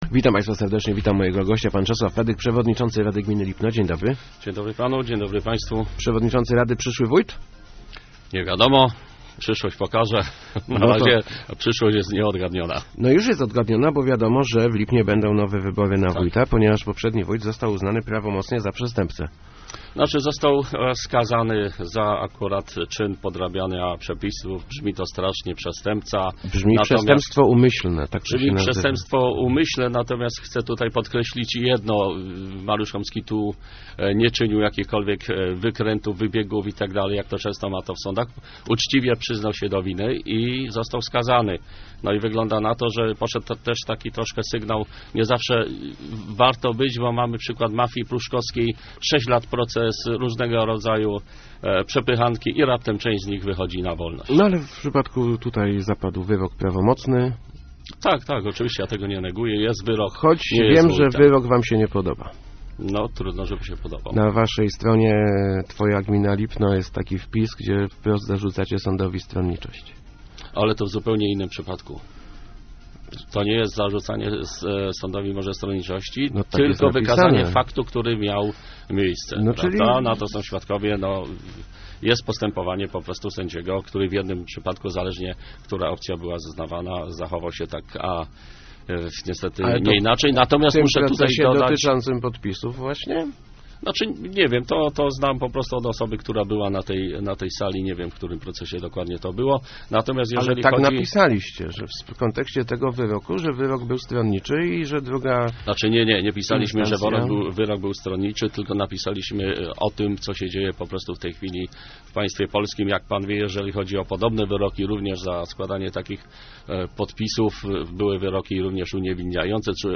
Jest taki pomysł, żebym kandydował na wójta - mówił w Rozmowach Elki Czesław Fedyk, przewodniczący Rady Gminy Lipno. Zapowiada on, że w kampanii będzie proponował mieszkańcom kontynuację działań byłego już wójta Mariusza Homskiego.